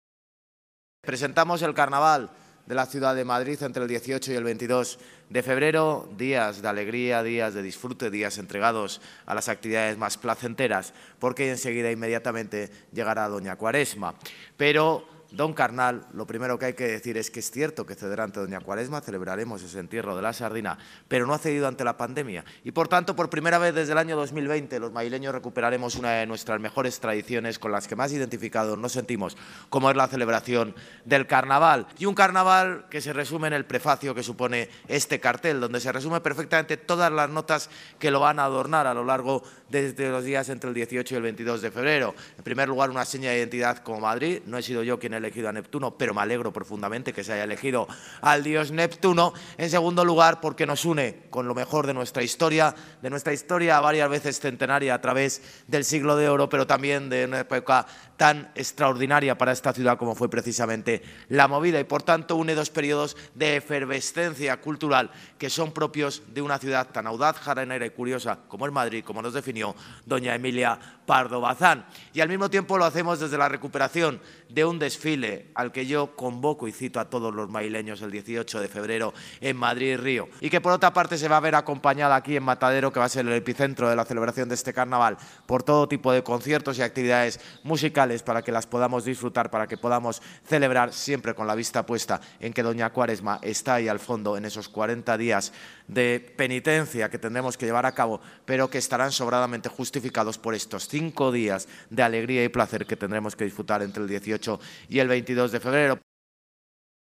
Presentación del carnaval 2023
José Luis Martínez-Almeida, alcalde de Madrid
JLMartinezAlmeida-PresentacionCarnaval-10-02.mp3